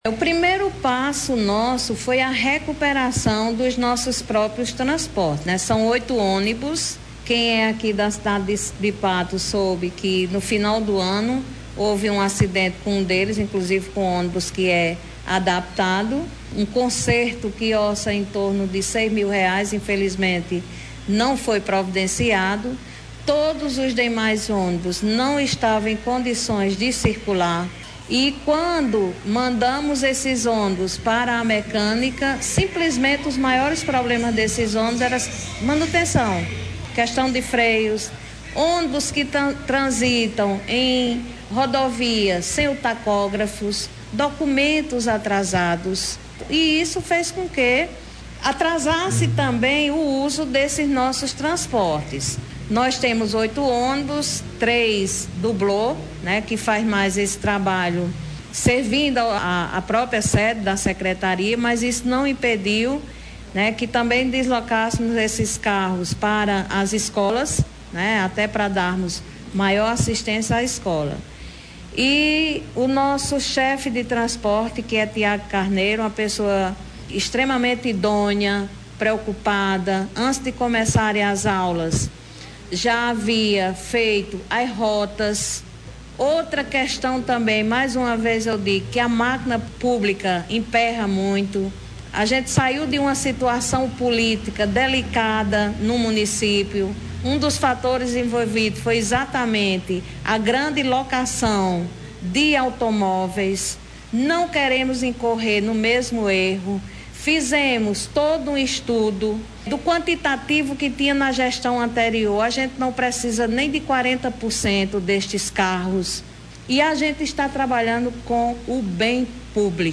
A Secretária de Educação do município de Patos, Alana Candeia, concedeu entrevista nesta quinta-feira, 16, onde esclareceu alguns acontecimentos registrados nos primeiros dias letivos do primeiro ano do governo Dinaldinho Wanderley.